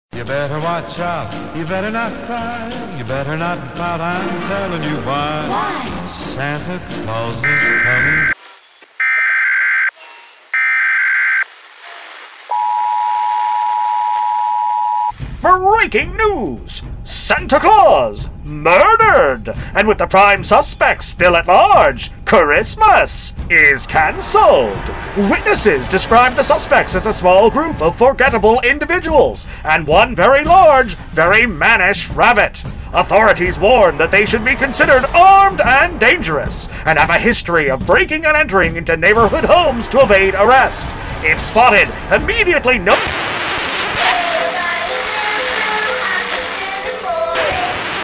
Each world intricately recreates familiar scenes laid out exactly as they once were, brought back to life through theatrical lighting transitions and their own iconic background music tracks, each leading with a thematic mini-preshow introductory announcement.